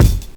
INSKICK07 -L.wav